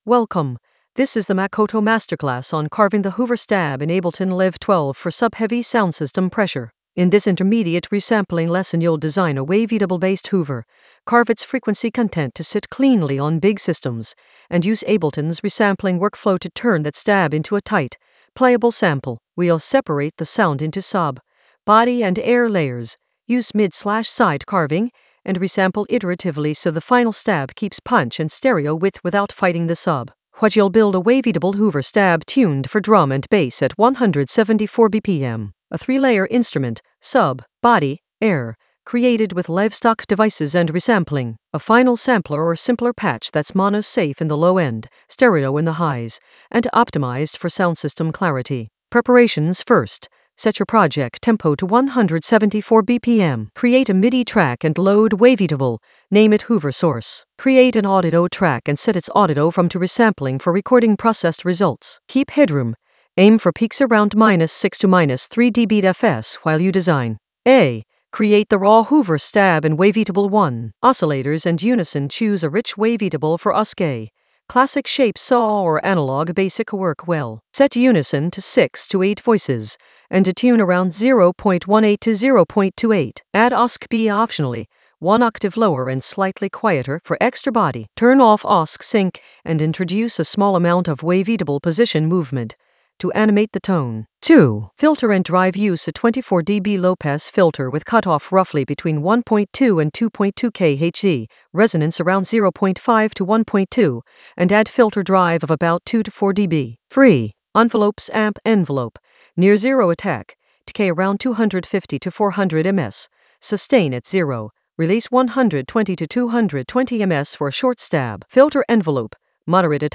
An AI-generated intermediate Ableton lesson focused on Makoto masterclass: carve the hoover stab in Ableton Live 12 for sub-heavy soundsystem pressure in the Resampling area of drum and bass production.
Narrated lesson audio
The voice track includes the tutorial plus extra teacher commentary.